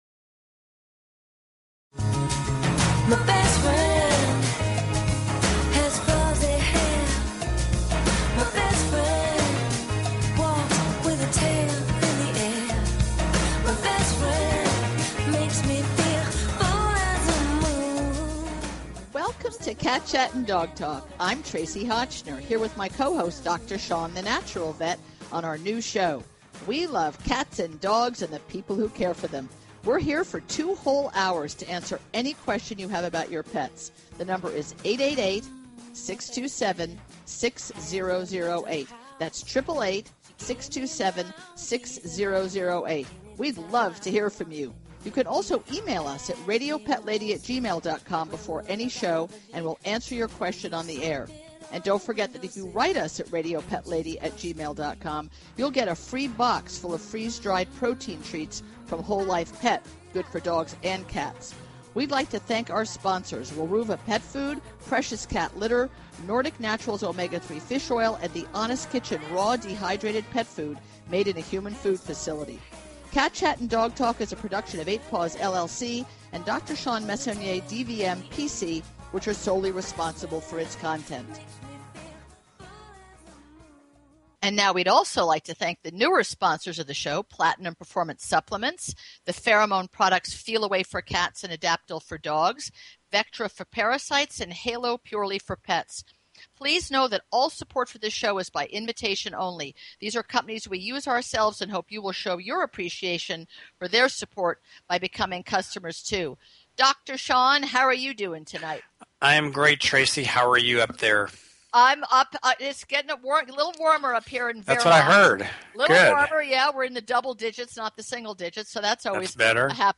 Talk Show Episode, Audio Podcast, Cat_Chat_and_Dog_Talk and Courtesy of BBS Radio on , show guests , about , categorized as